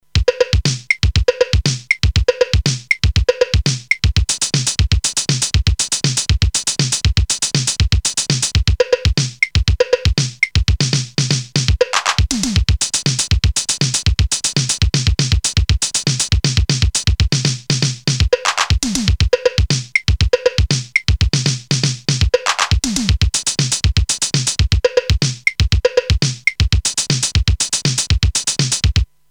Hand-held beat box with digital samples and very minimal sequencer.
drum machine
1- BANK 1 is based on a acoustic drum set
2- BANK 2 contains effects like barking dog or all-time favorite car horns (TIP: to access fx bank press hihat then switch on).
There are 8 preset styles rhythms like samba, disco, rock with fill-in variations mode.